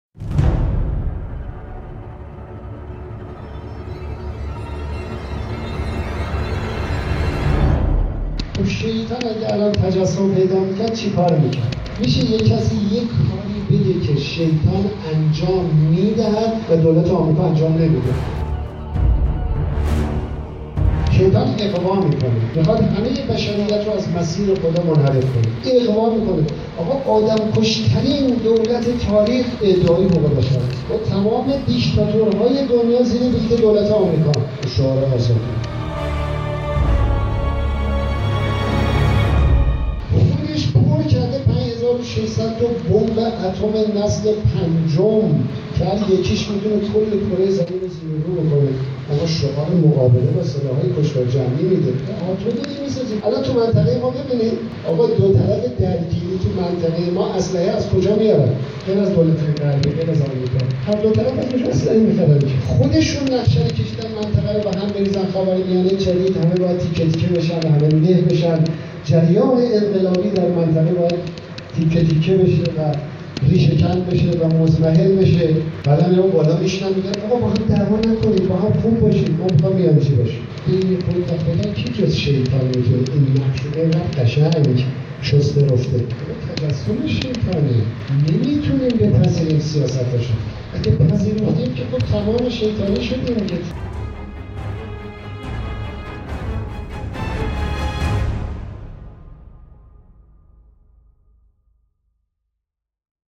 احمدی نژاد به حدی با حضور ایران در سوریه و مقابله با طراحی صهیونیست ها جدیت دارد که حتی در اوج جنایت داعش در سوریه در یک سخنرانی دخالت در این جنگ را شیطانی خواند.